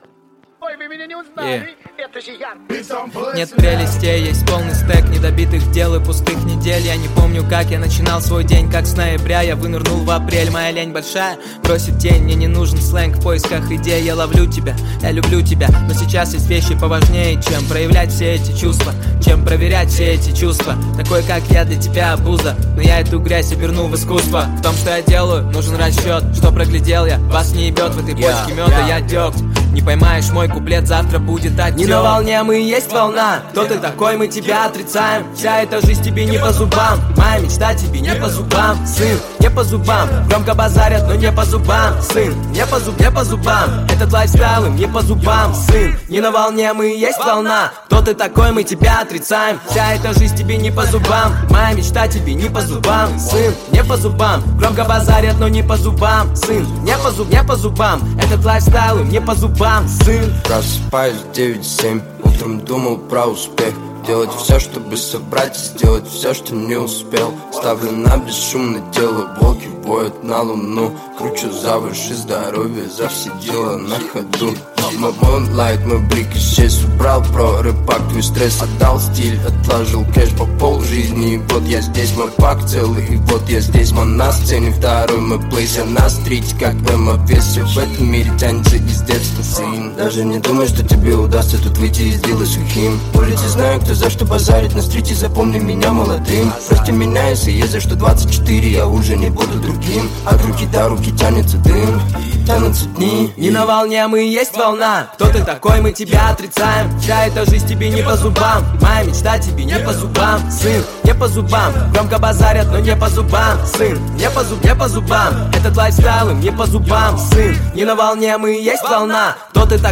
Росийский рэп-исполнитель и автор песен